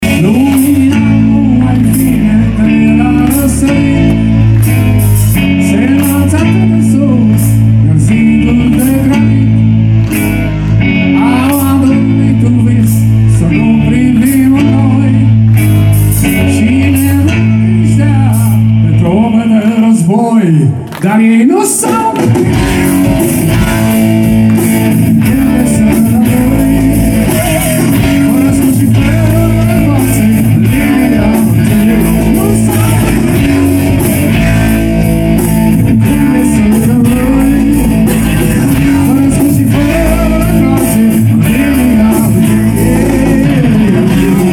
a susținut un show incendiar în auditoriumul USV
naționala de rock a României
iar publicul a cântat alături de ei.